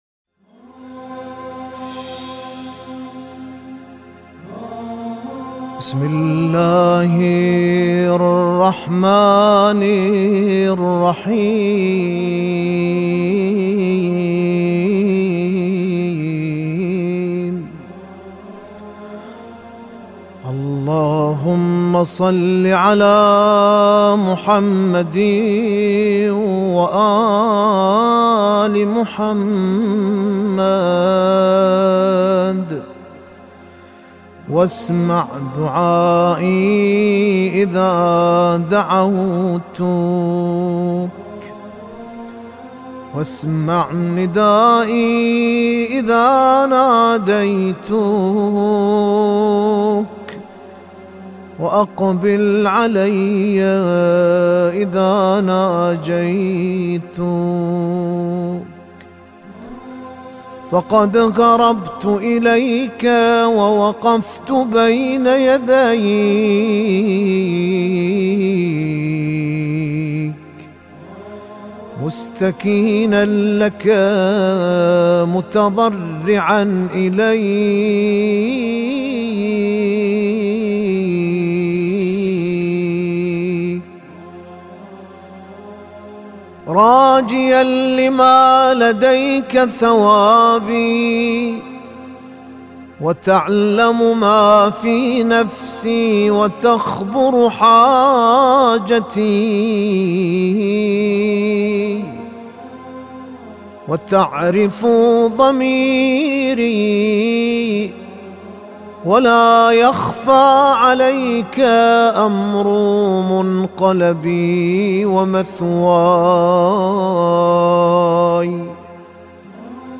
Munajat Shabaniya Read by Iranian Mubtahil